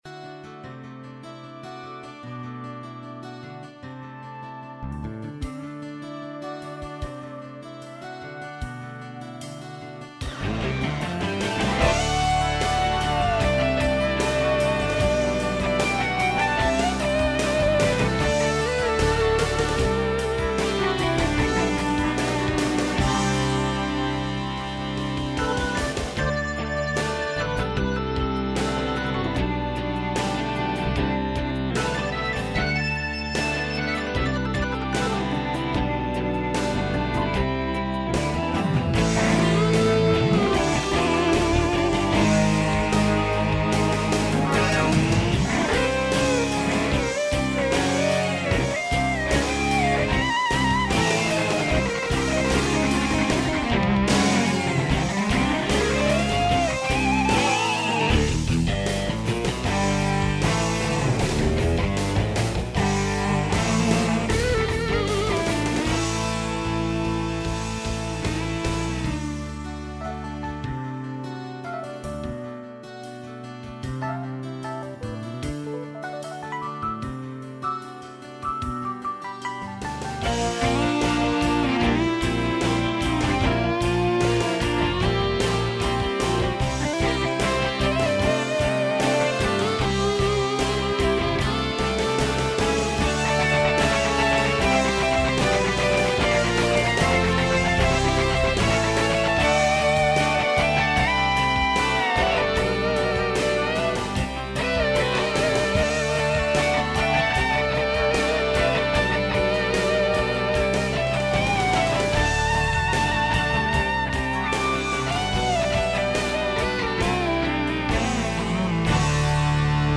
Synthesis: PCM rompler
demo factory demo 2